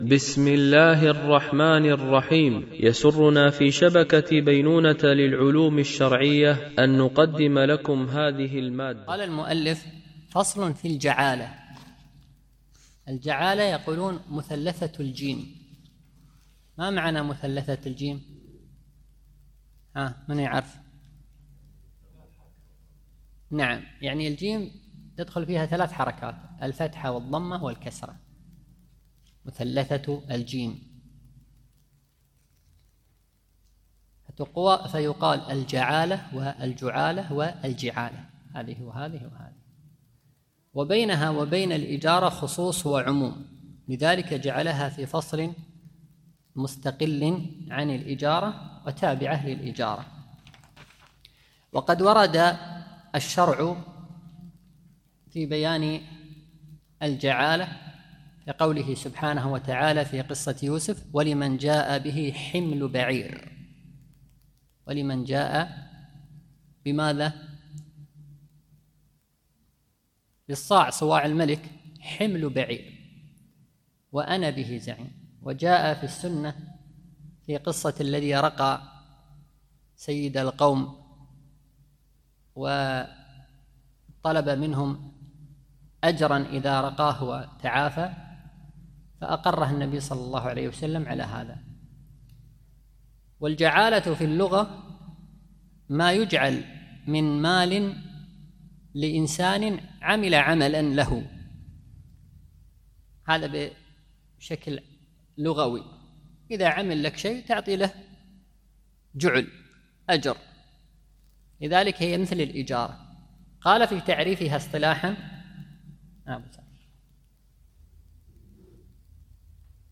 شرح الفقه المالكي ( تدريب السالك إلى أقرب المسالك) - الدرس 78 ( كتاب البيوع )